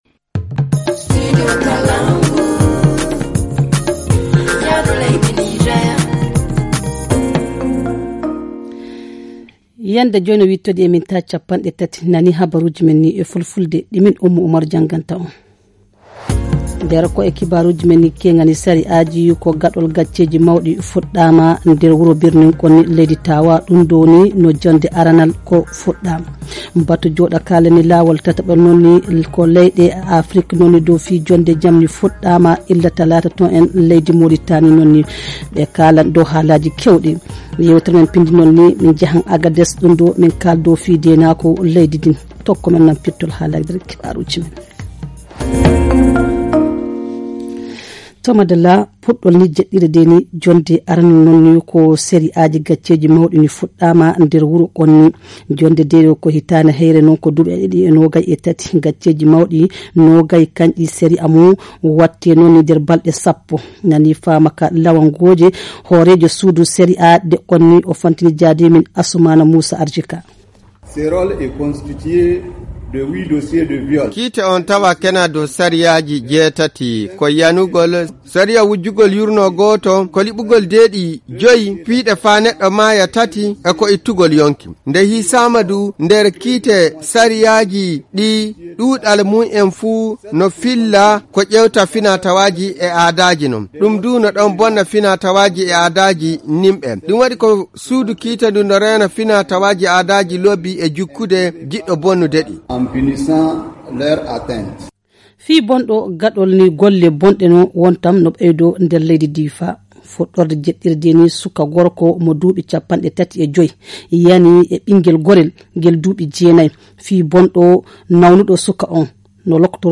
Le journal du 19 janvier 2023 - Studio Kalangou - Au rythme du Niger